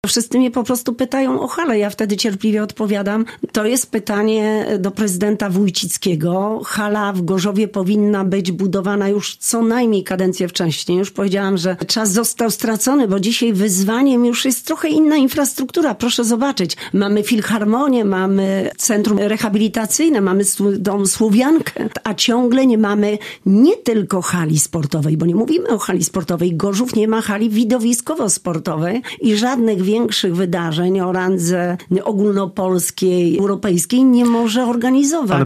Hala sportowa w Gorzowie powinna była być budowana już w poprzedniej kadencji samorządowej. Nie może być tak, że obecny prezydent Gorzowa nie podejmuje wyzwania, bo obawia się o finanse – uważa Elżbieta Rafalska – minister rodziny, pracy i polityki społecznej , poranny gość Radia Gorzów.